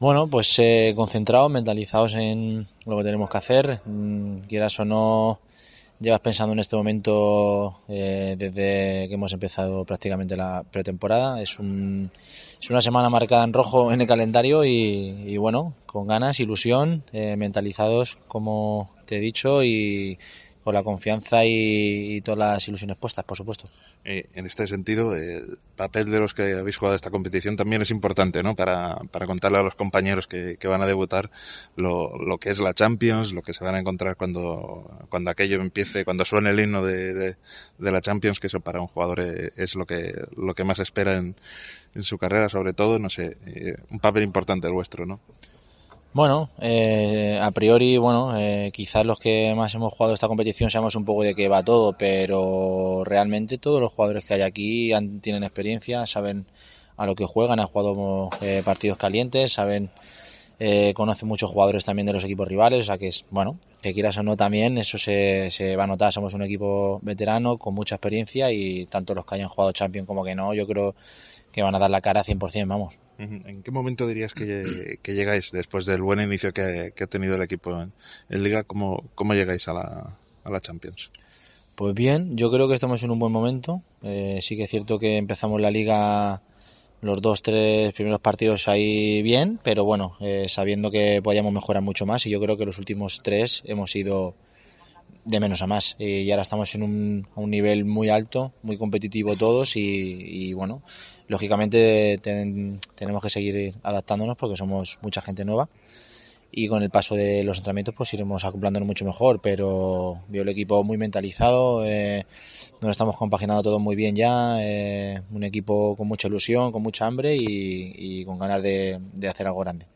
Hablamos con el jugador de esta ronda principal ante Sporting París, Kairat y Anderlecht en una cita histórica para el club.